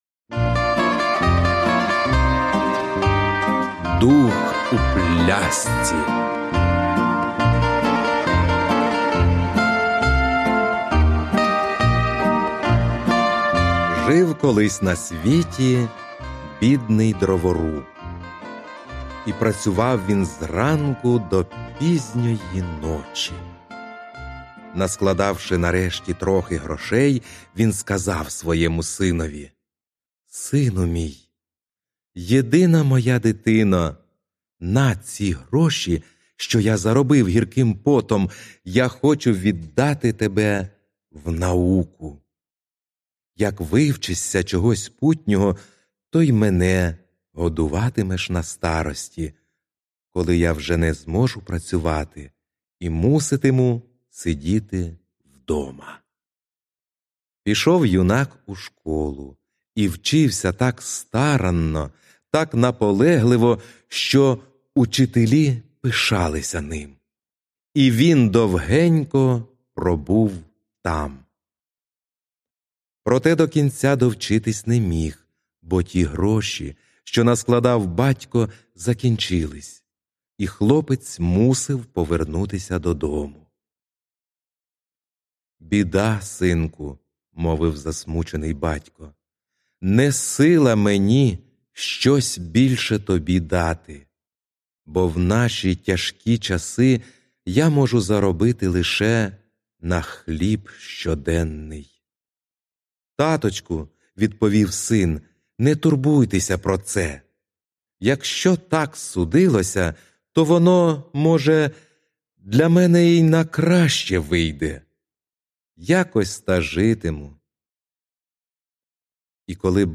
Аудіоказка Дух у пляшці